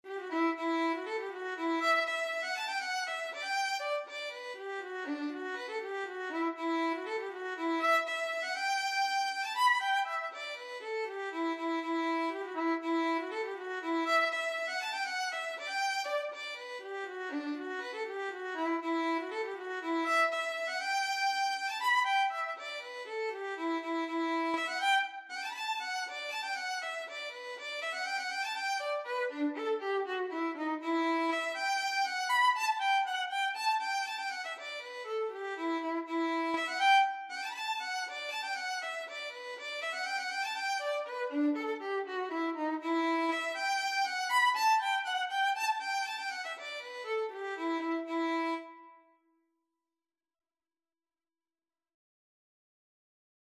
E minor (Sounding Pitch) (View more E minor Music for Violin )
6/8 (View more 6/8 Music)
Violin  (View more Intermediate Violin Music)
Traditional (View more Traditional Violin Music)
Irish